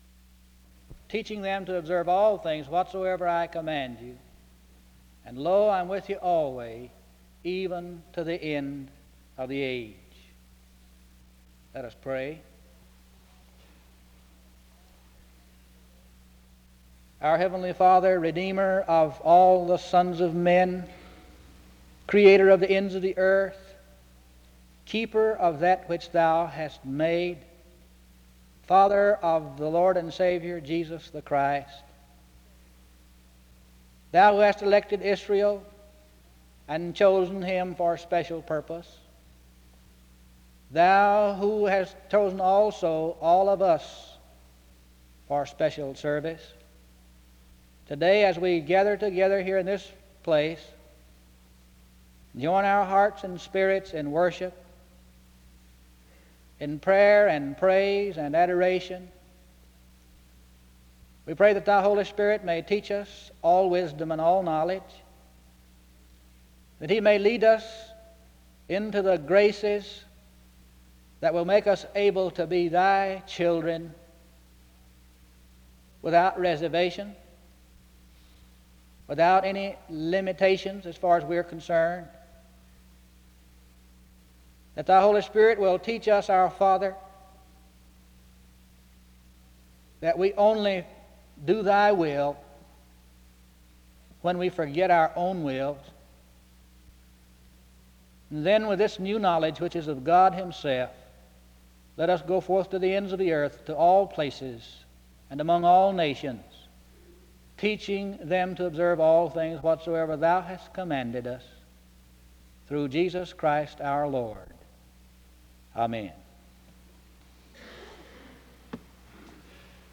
The scripture reading was a mixed reading drawn from John, 1 Corinthians, Romans, and Matthew.
In Collection: SEBTS Chapel and Special Event Recordings SEBTS Chapel and Special Event Recordings